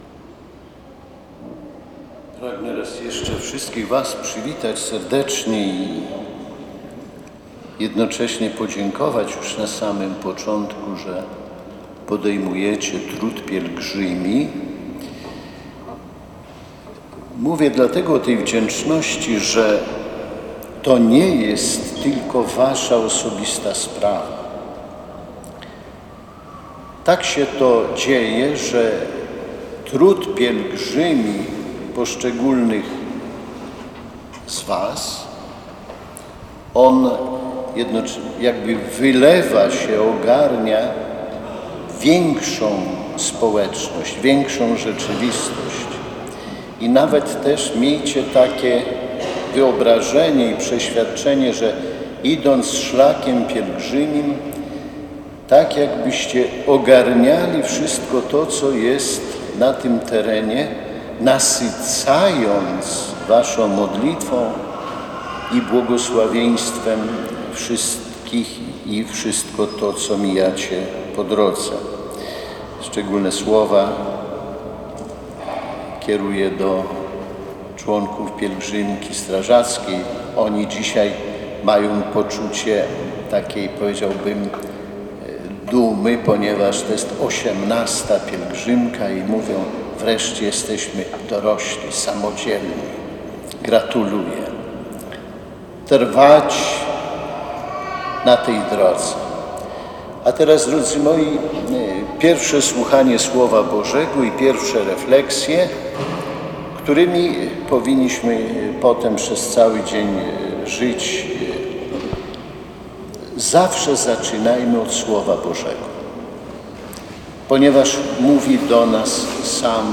Poranną Mszą św. o godzinie 7:00 w Sanktuarium Matki Bożej Ostrobramskiej rozpoczęła się dziś 41. Warszawsko-Praska Piesza Pielgrzymka na Jasną Górę.
Homilię podczas Mszy św. wygłosił biskup diecezji warszawsko-praskiej Romuald Kamiński. Wasz trud i modlitwa nasycają wszystkich, których spotykacie na swojej drodze – powiedział hierarcha.